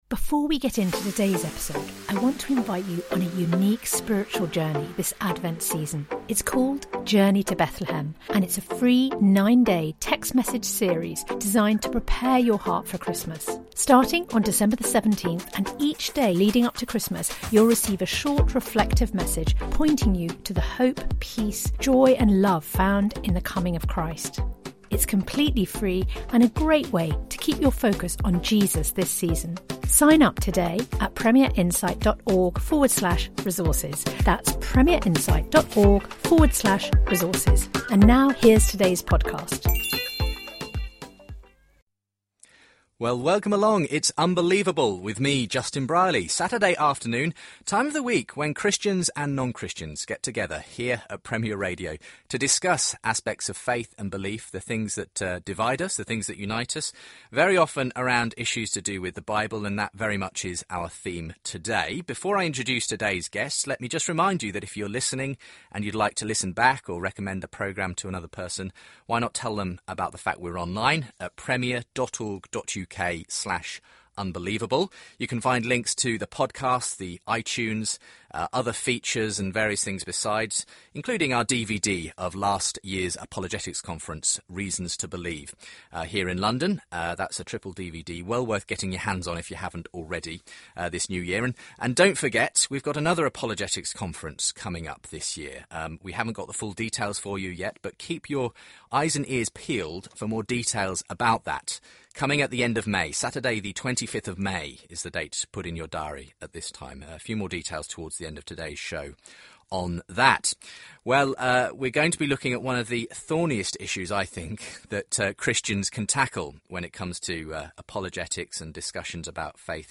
For more Christian/non-Christian debate